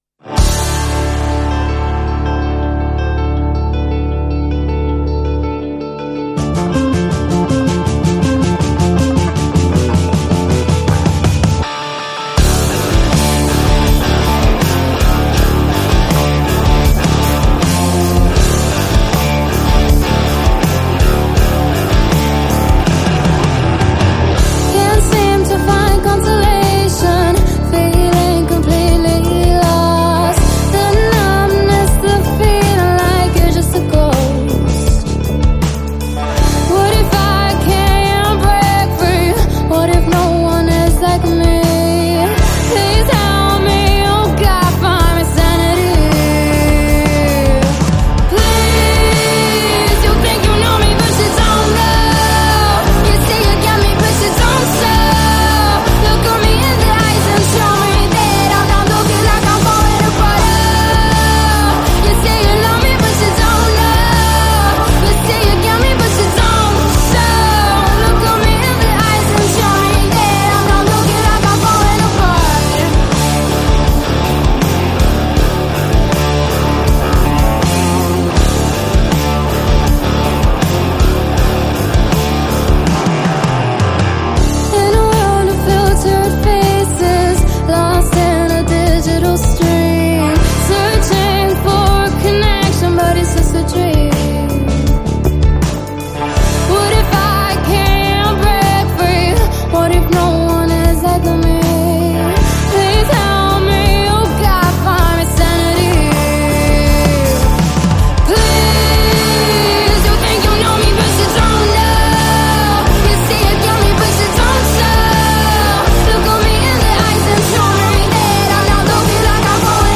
rock band
cantante
bassista
chitarrista
batterista